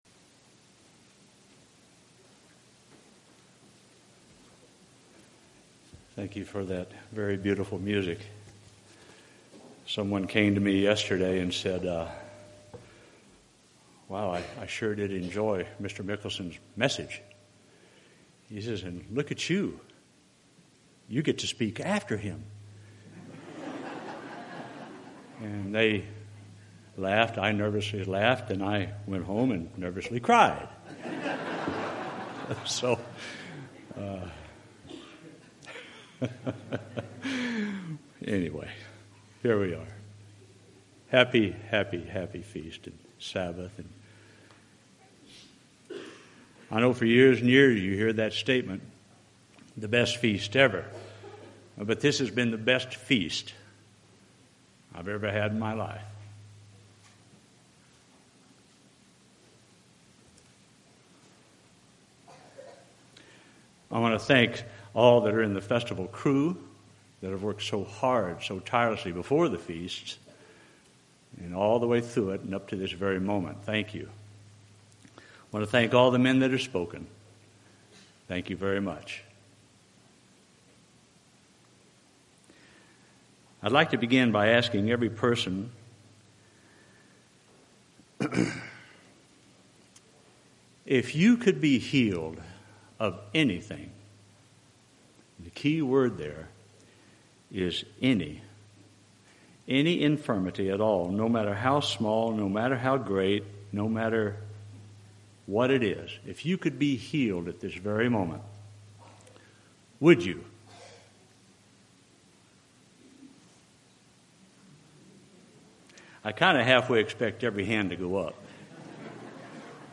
This sermon was given at the Phoenix, Arizona 2016 Feast site.